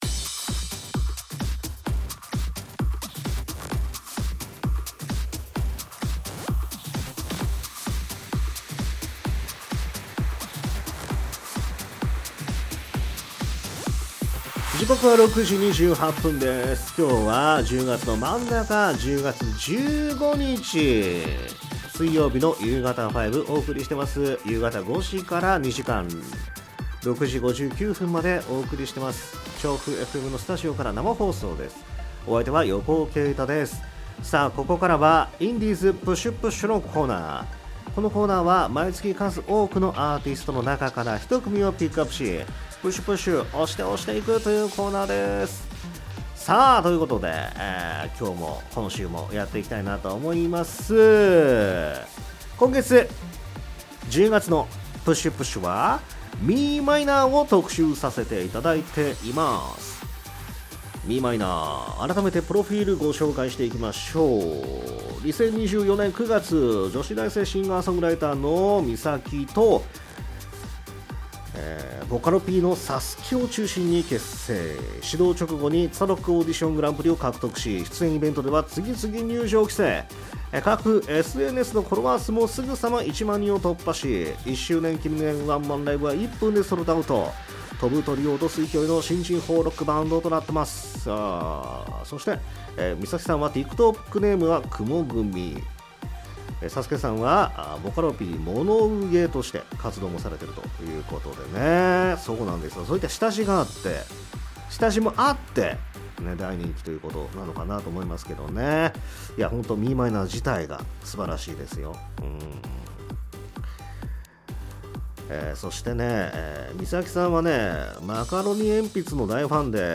※アーカイブでは楽曲カットしています